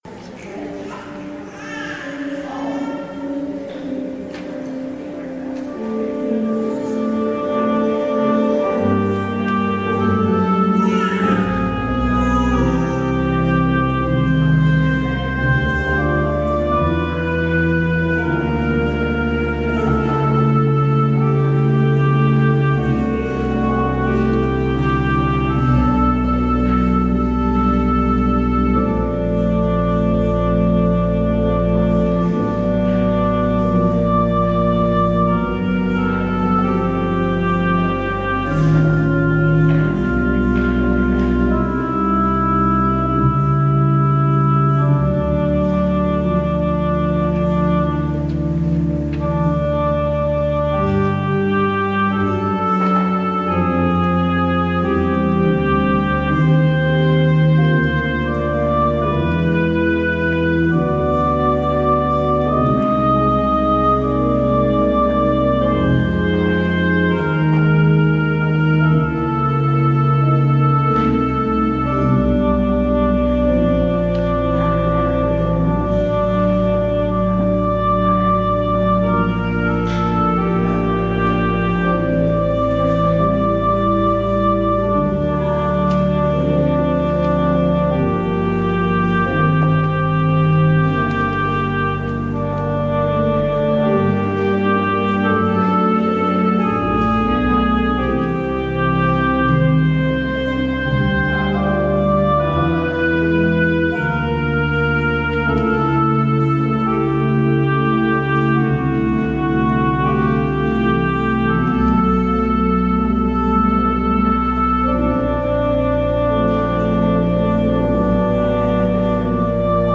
Audio recording of the 10am hybrid/streamed service